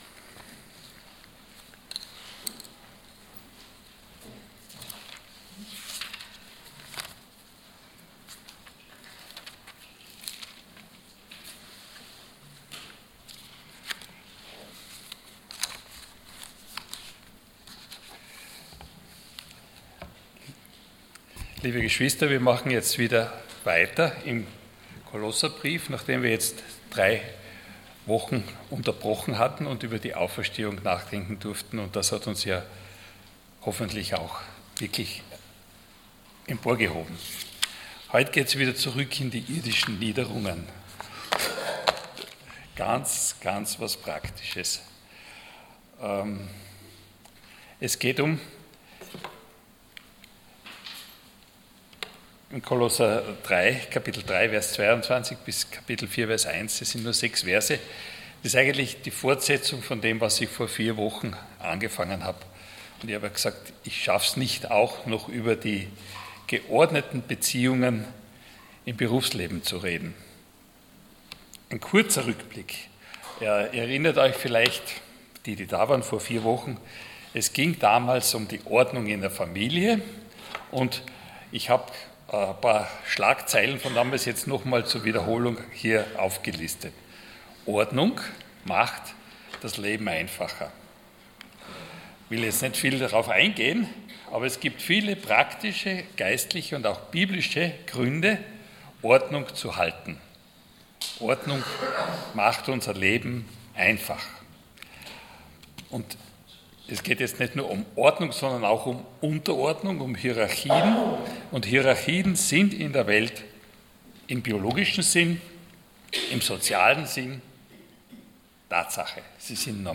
Passage: Kolosser 3,22-4,1 Dienstart: Sonntag Morgen